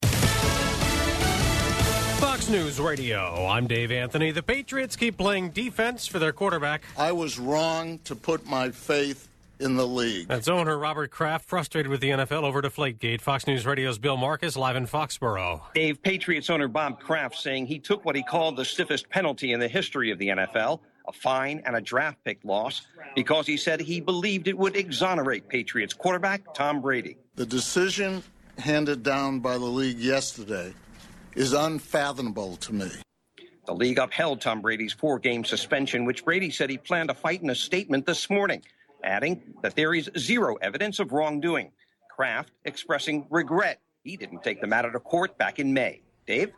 (FOXBOROUGH, MASSACHUSETTS) JULY 29 – FOX NEWS RADIO, LIVE, 11AM –